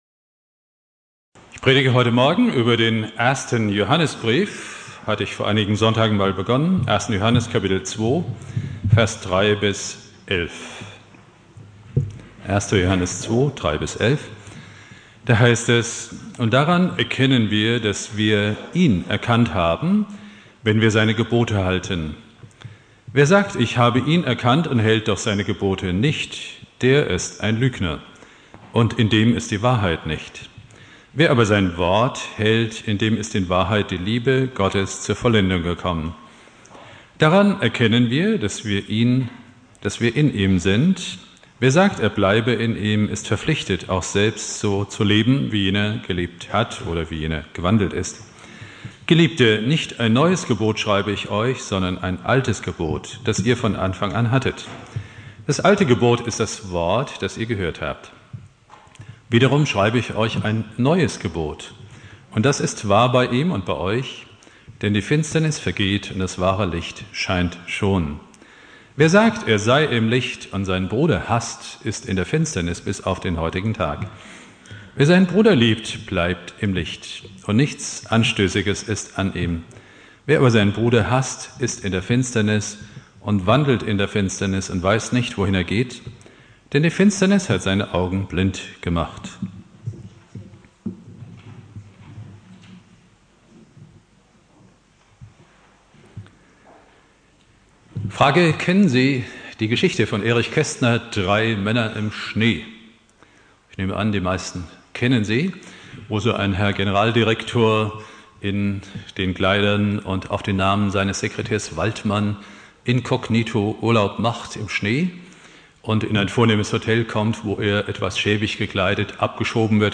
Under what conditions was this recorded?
Ewigkeitssonntag Prediger